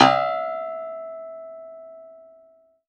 53x-pno05-E3.wav